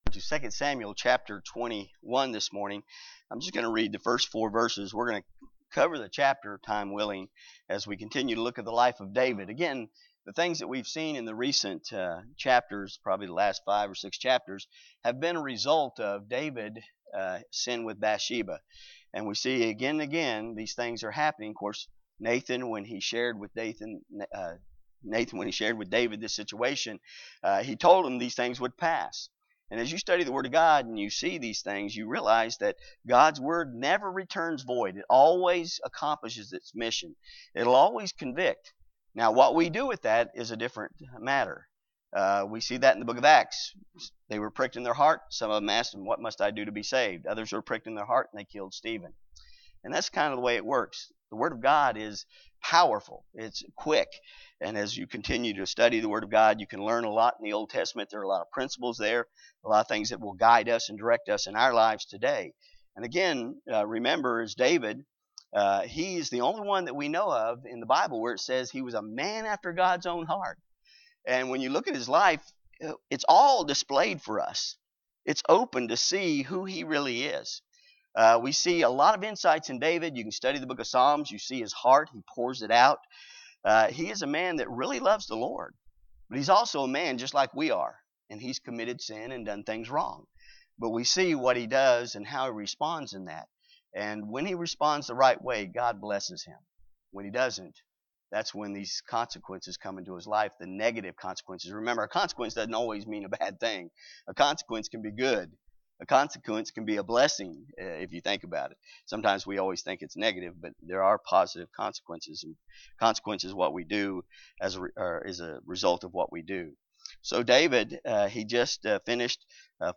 Series: II Samuel Study Passage: II Samuel 21:1-14 Service Type: Sunday School
Topics: Preaching